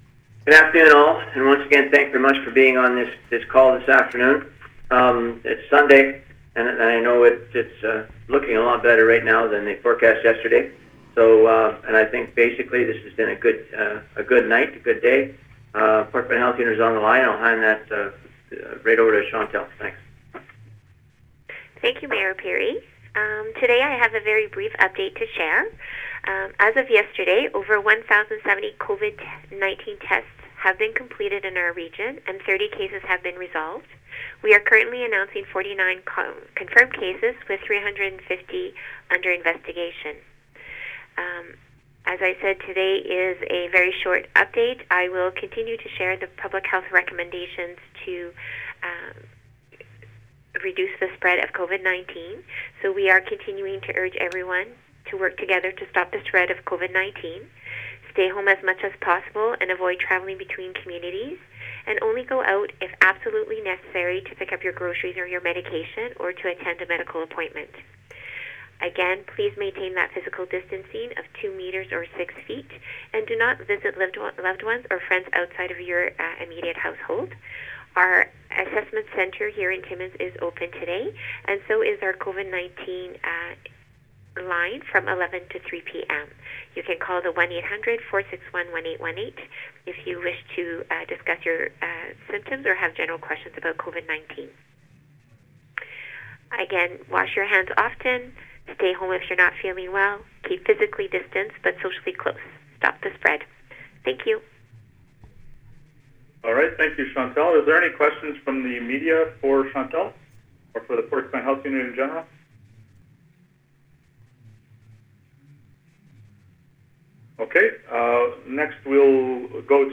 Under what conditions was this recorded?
Here is the raw audio of today’s call: